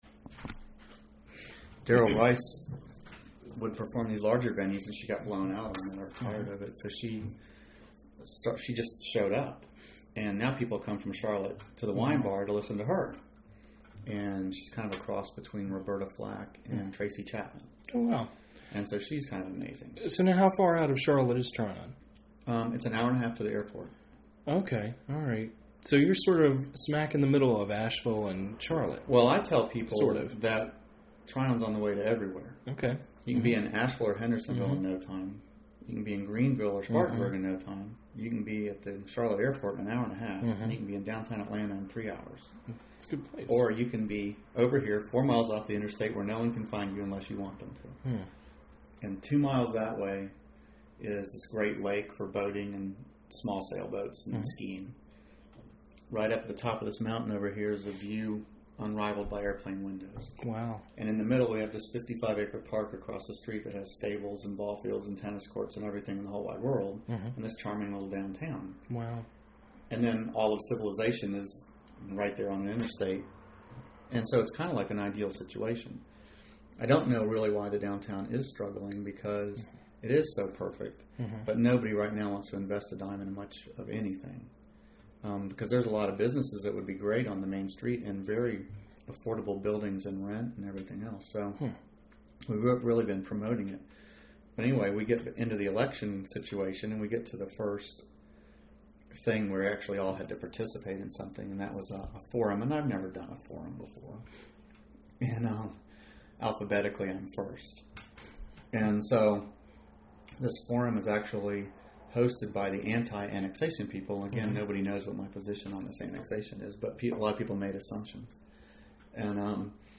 Appalachian Lesbian, Gay, Bisexual, and Transgender Oral History Project